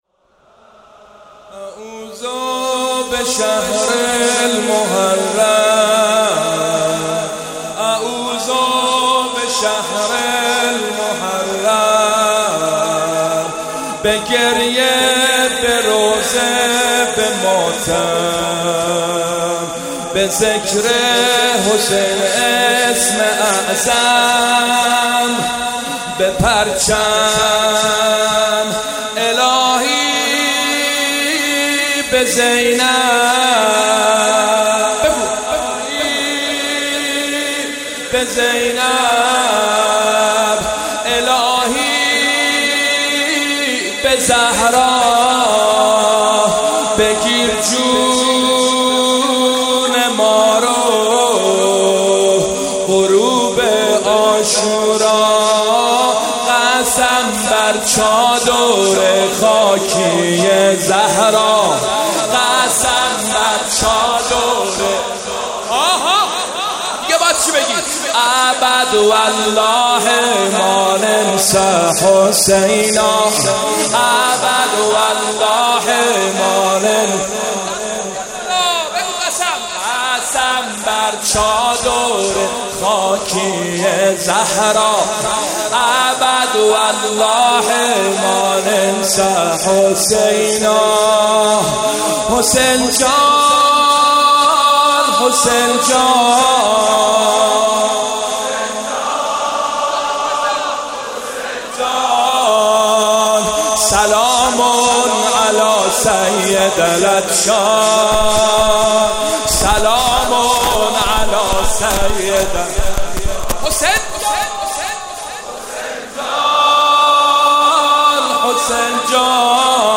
مداحی
در شب اول محرم